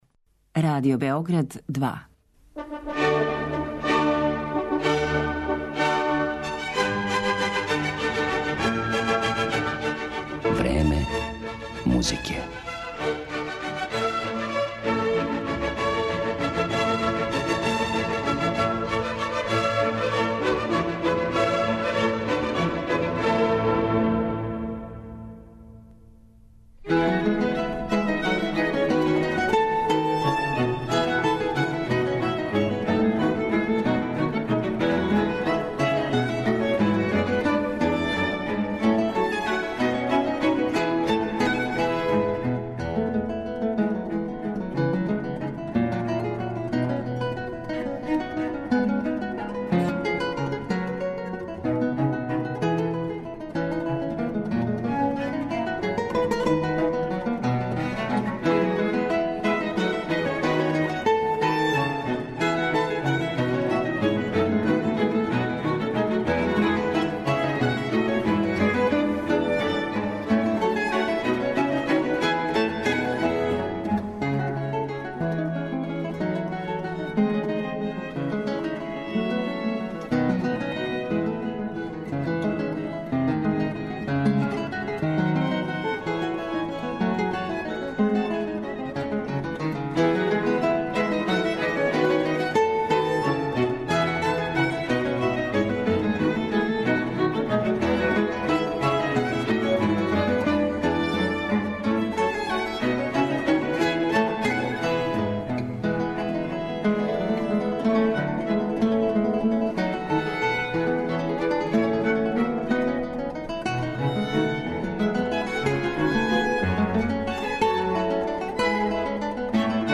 учила гитару и овом инстурменту се професионално посветила.